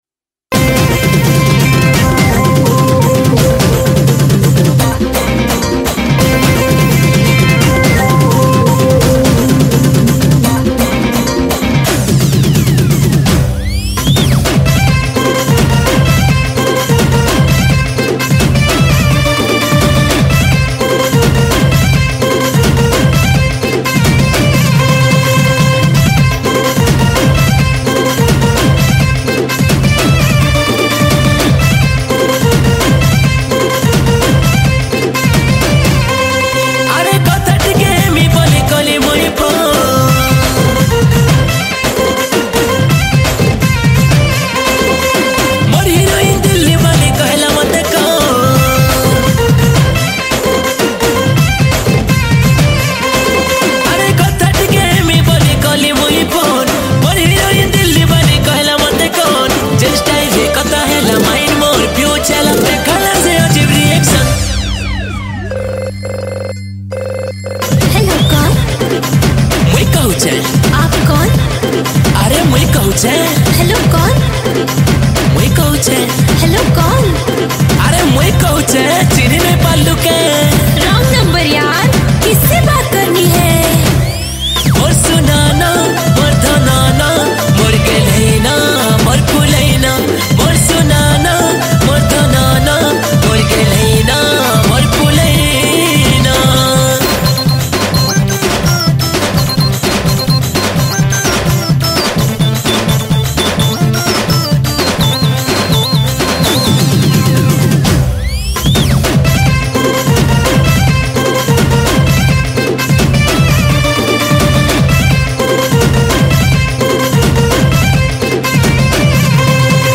Sambapuri Single Song